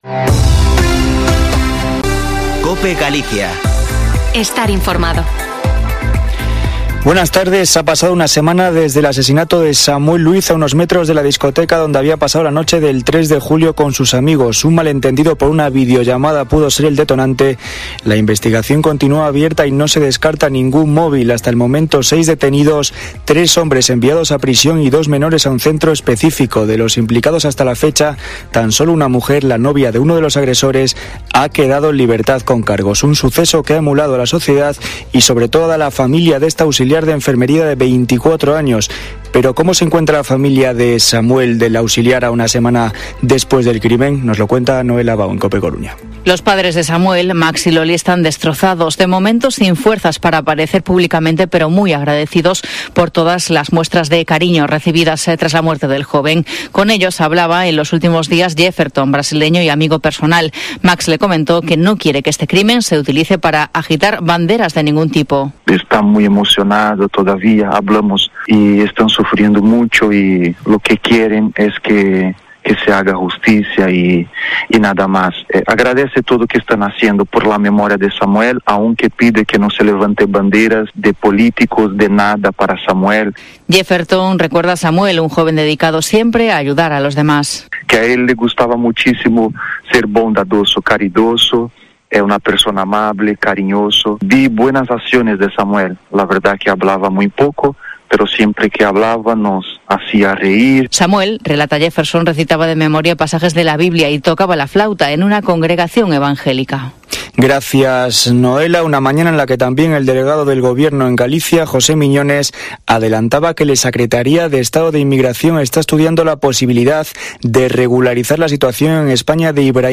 Informativo Mediodía en Cope Galicia 13/07/2021.